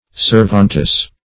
Servantess \Serv"ant*ess\, n.